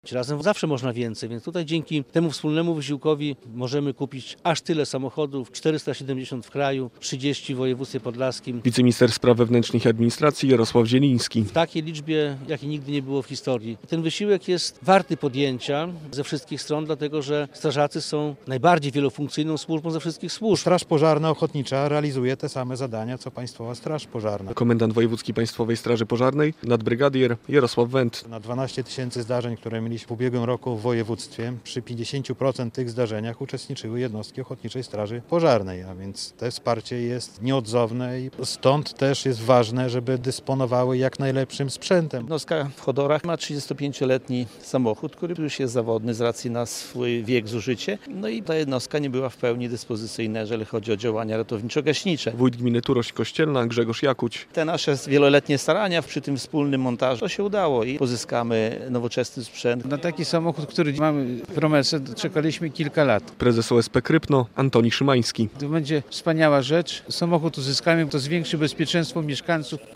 relacja
- Zakup 30 wozów dla straży pożarnej jest możliwe dzięki współpracy wielu podmiotów - mówił w poniedziałek (8.04) w Białymstoku wiceminister spraw wewnętrznych i administracji Jarosław Zieliński.